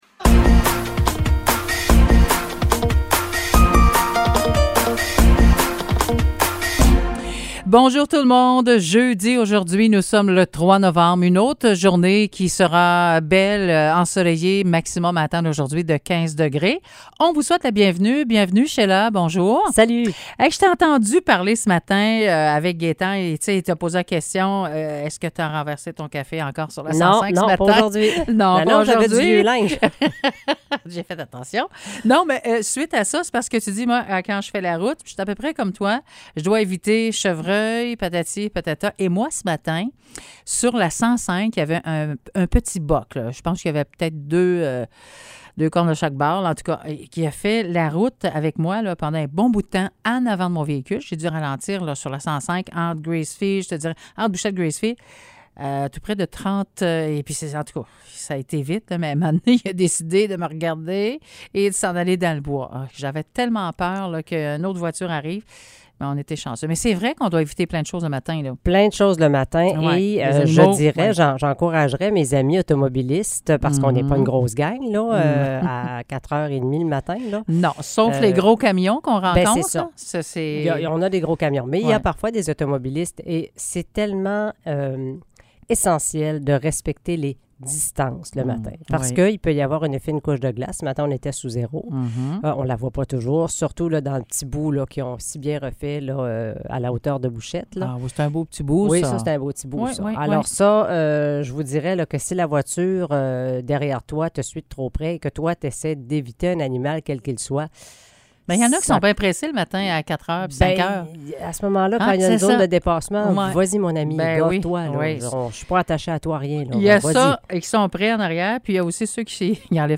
Nouvelles locales - 3 novembre 2022 - 9 h